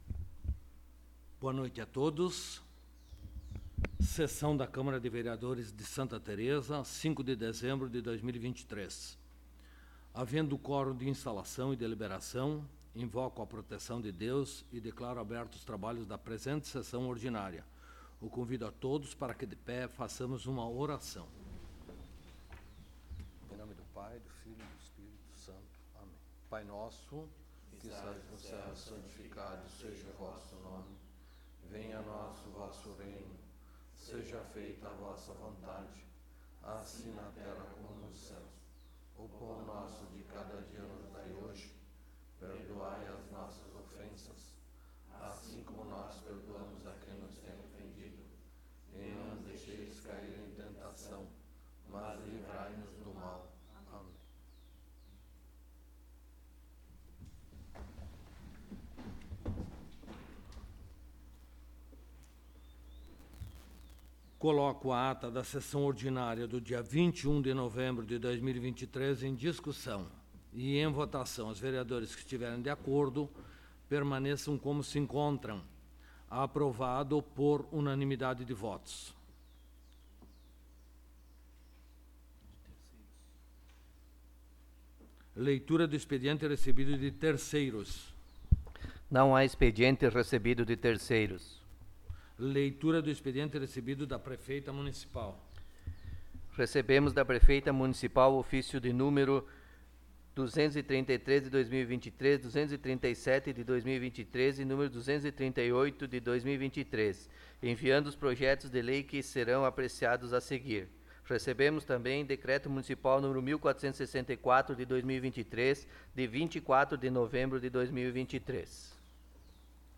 21ª Sessão Ordinária de 2023
Áudio da Sessão